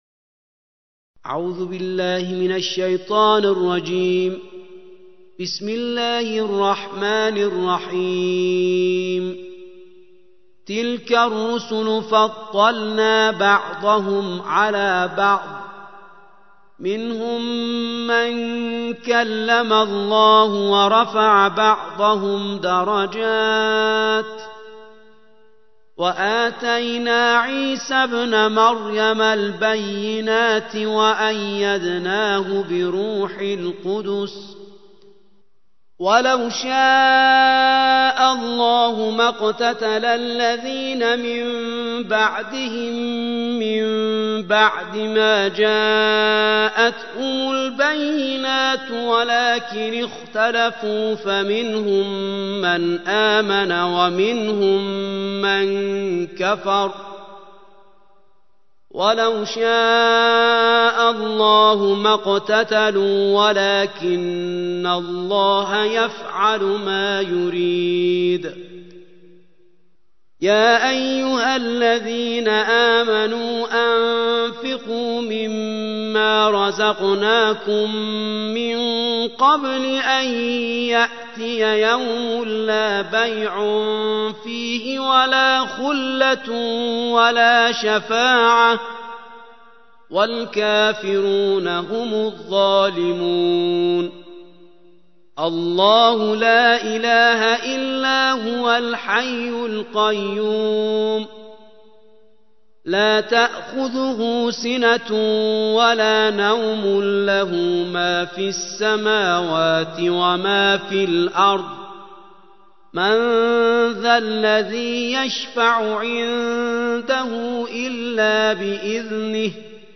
الجزء الثالث / القارئ